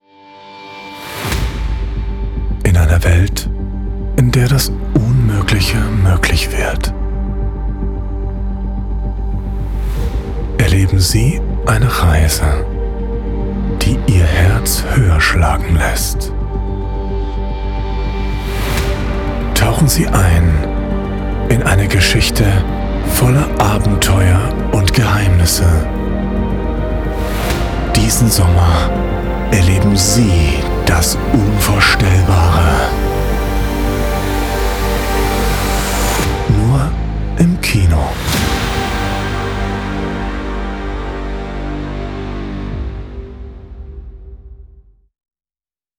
Kommerziell, Tief, Unverwechselbar, Zuverlässig, Warm
Erklärvideo